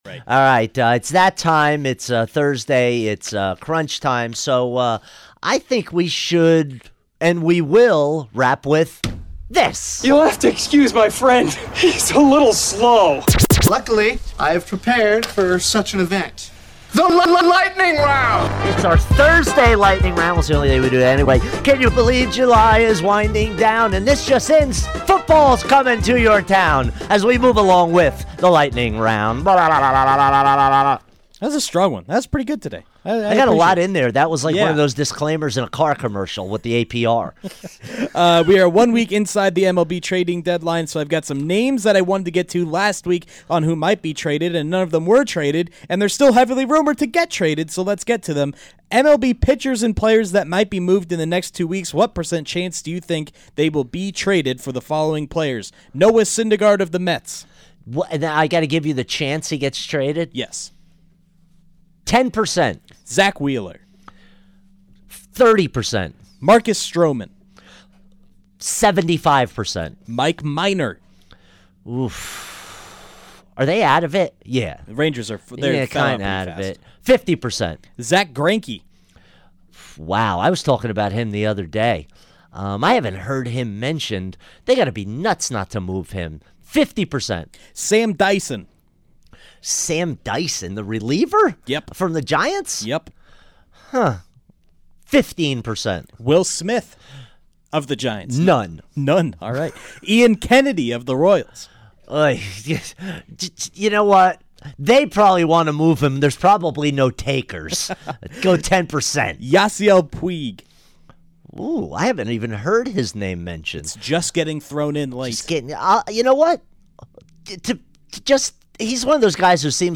rapid-fire style